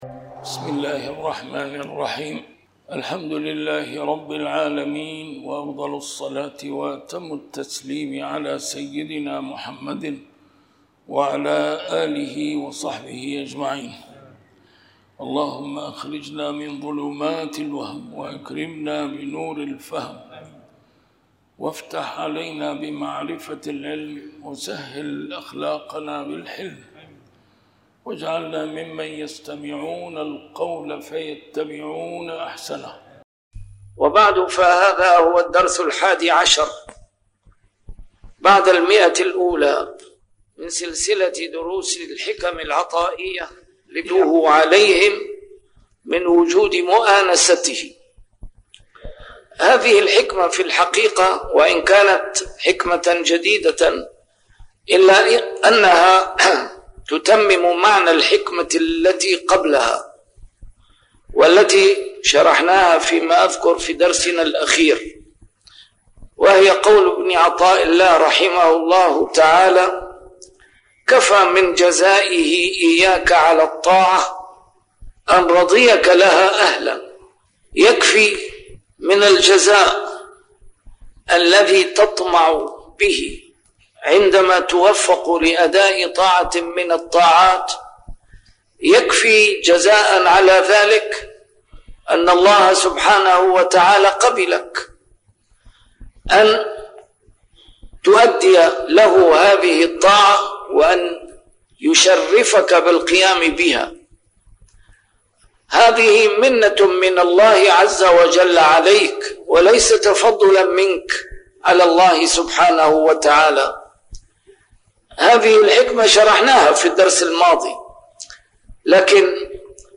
الدرس رقم 111 شرح الحكمة 91